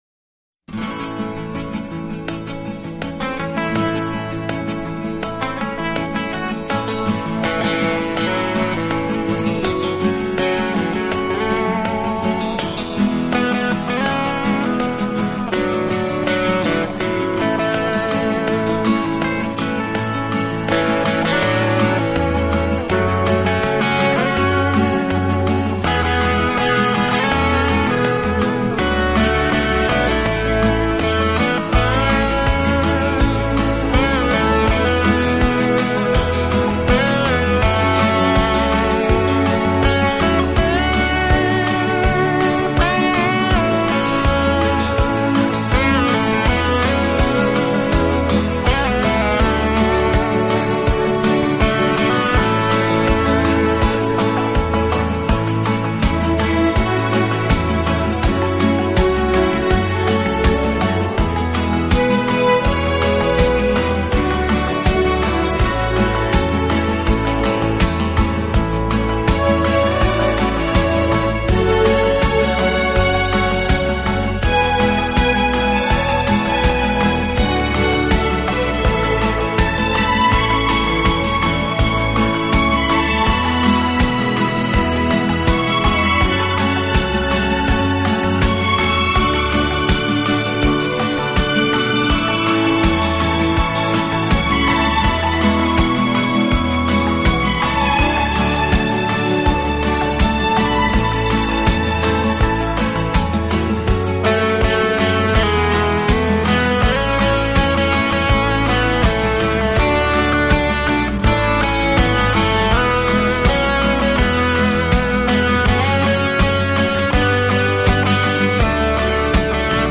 【顶级轻音乐】
世界三大轻音乐团